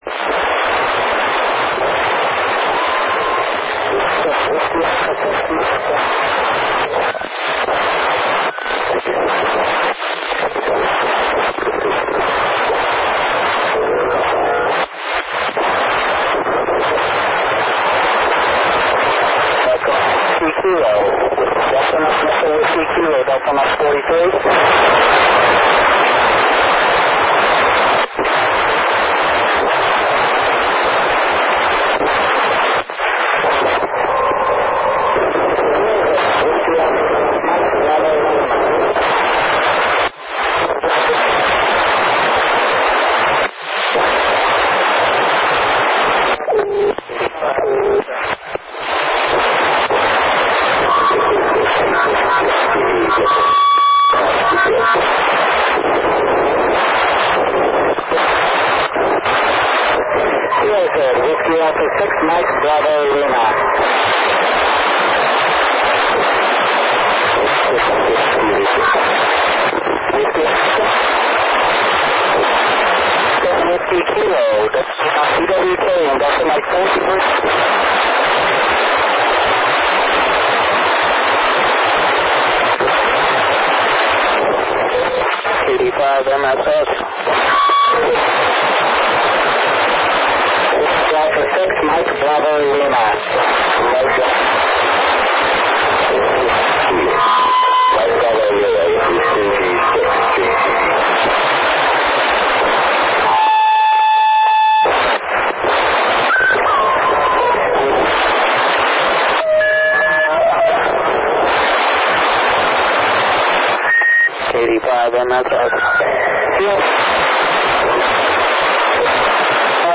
ISS cross-band repeater, 31 December 2005 @ 0012 UTC
I used an Icom IC-W32A HT at 5W with an Arrow Antennas handheld Yagi to record this pass (and make some QSOs during the pass).